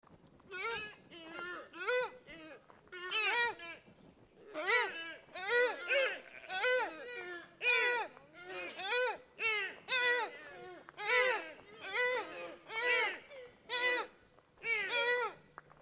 Download Deer sound effect for free.
Deer